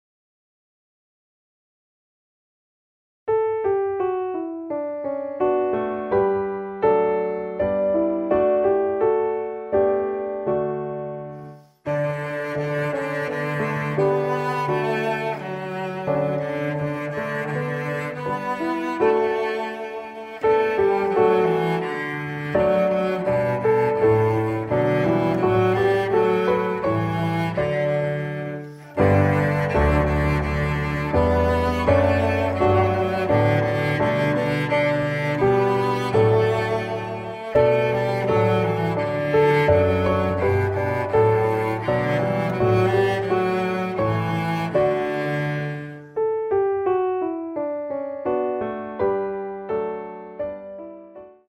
• für 1-2 Celli